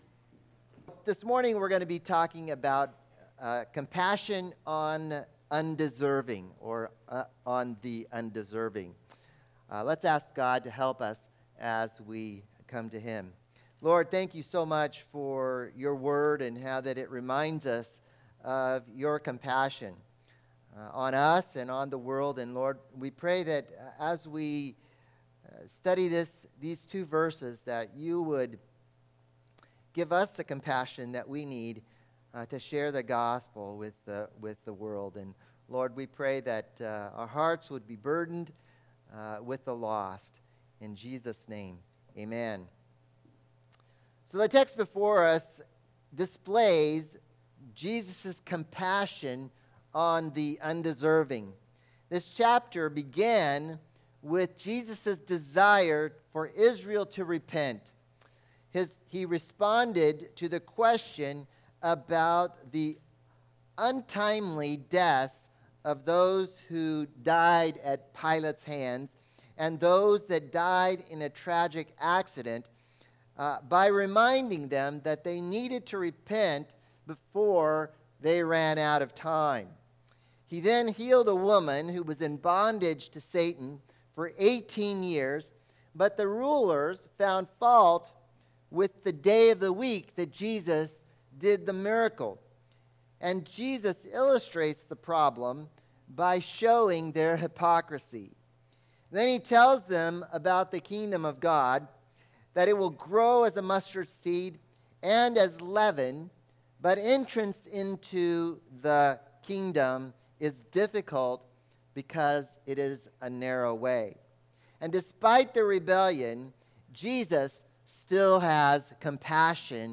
Bible Text: Luke 13:34-35 | Preacher